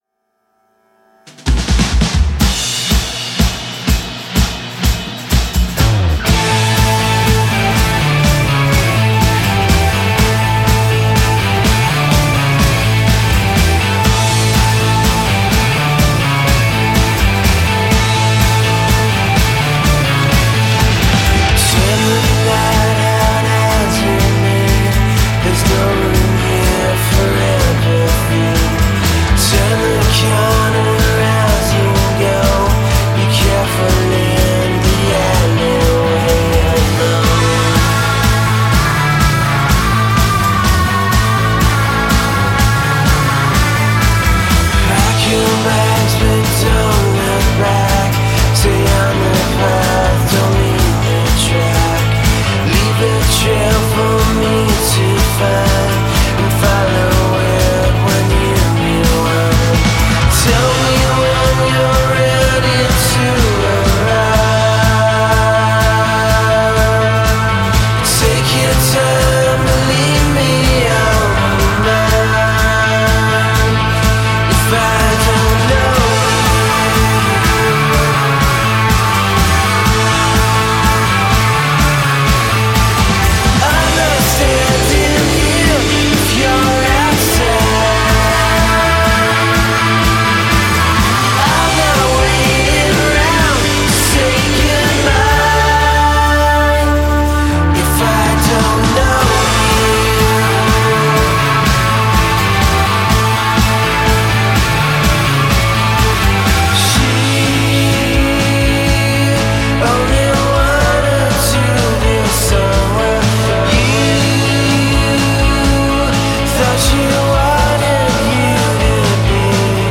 psychedelic rock band